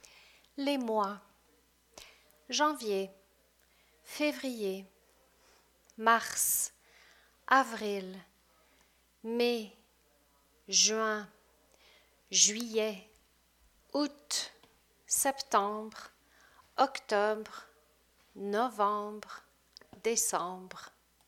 Note: more often that not, French speakers in Canada do not pronounce the final t of août.